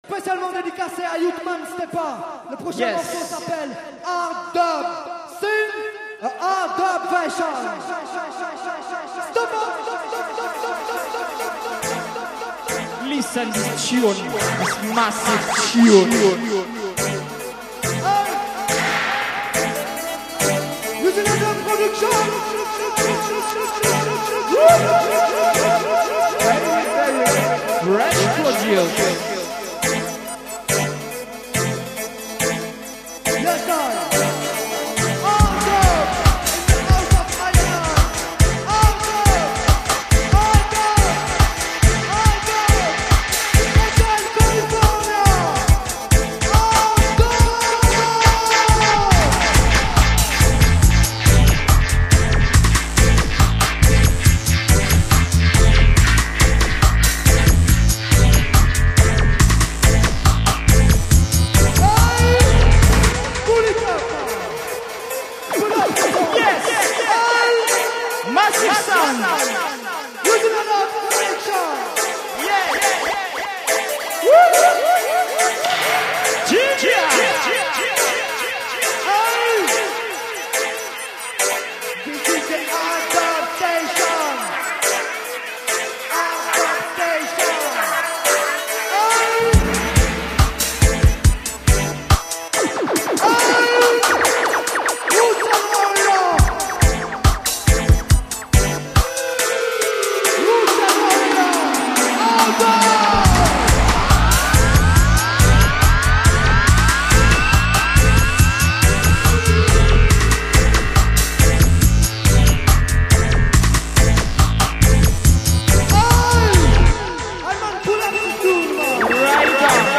DUbSesSion iNna GeNèVA (Switzerland)
live&direct